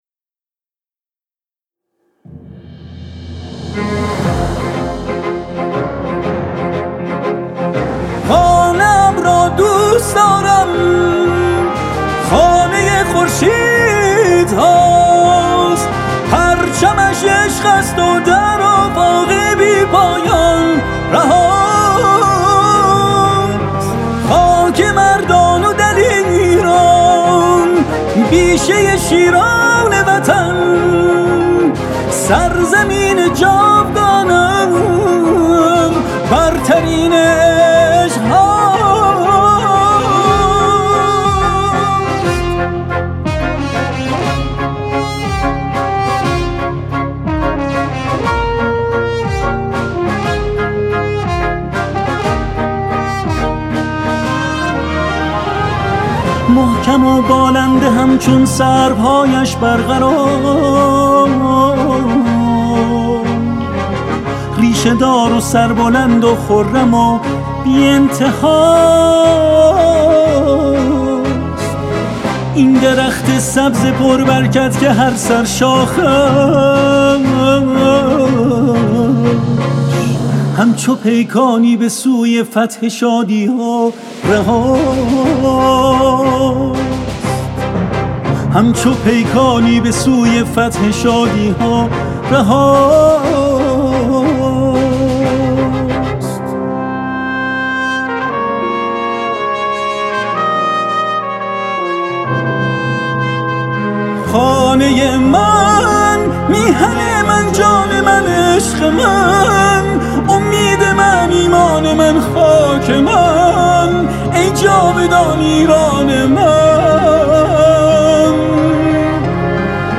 ملی و حماسی